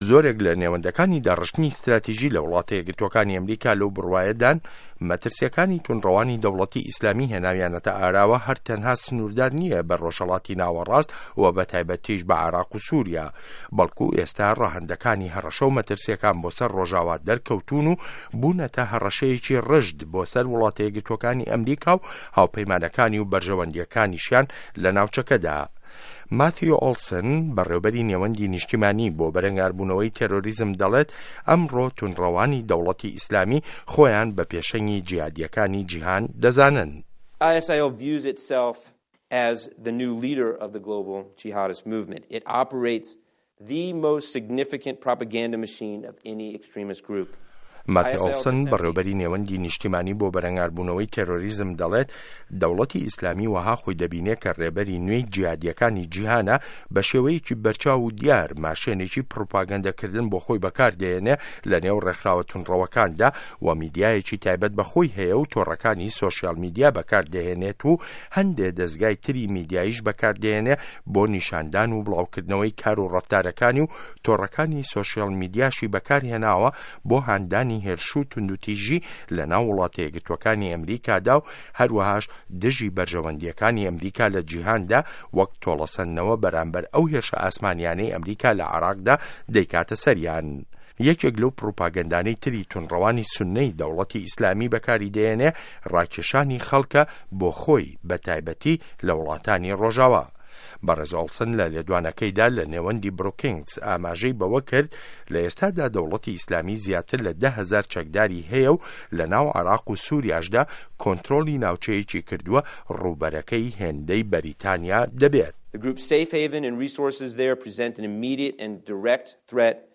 ڕاپۆرت له‌سه‌ر بنچینه‌ی لێدوانه‌کانی ماثیو ئۆڵسن